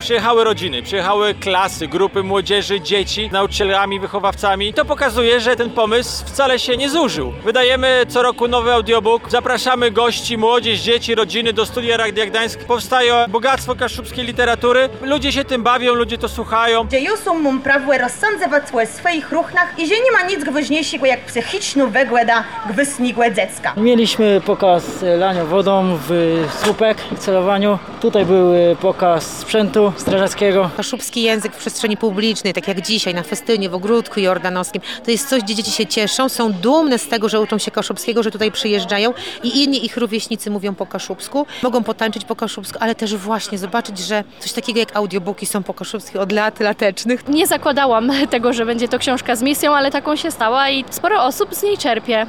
Posłuchaj materiału reportera: